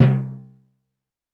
Drums_K4(46).wav